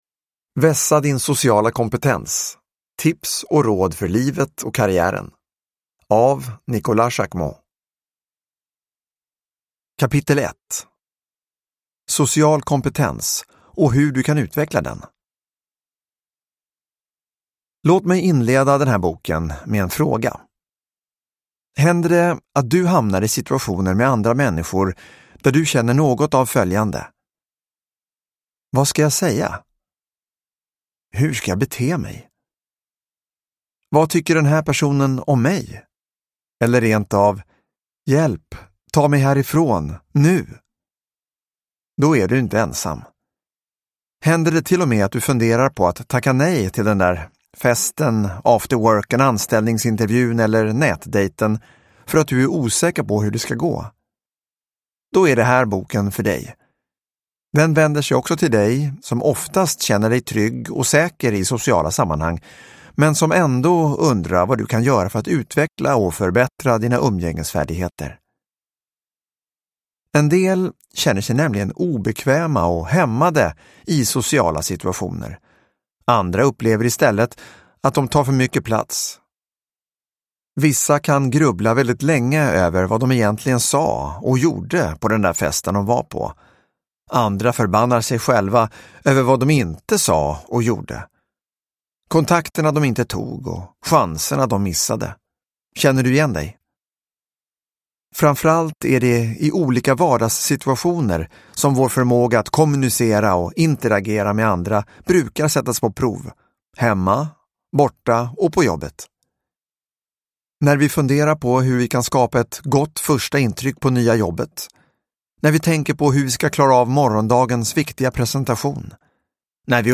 Vässa din sociala kompetens : tips och råd för livet och karriären – Ljudbok – Laddas ner